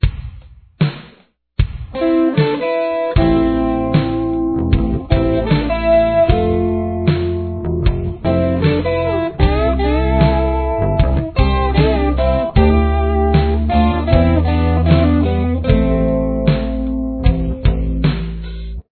Intro Riff
The intro riff is composed of two guitars playing harmony.